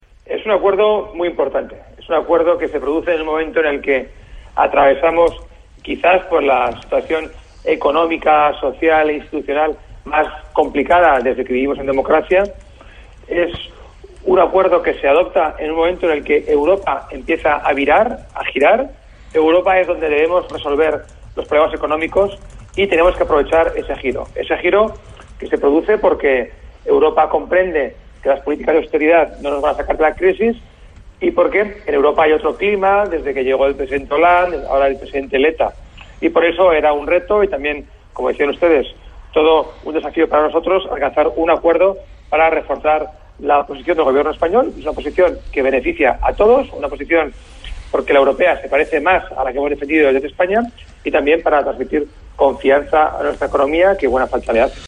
Fragmento de una entrevista en RNE a Juan Moscoso del Prado.